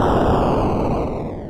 描述：复古视频游戏8位爆炸
Tag: 复古 爆炸 8位 视频游戏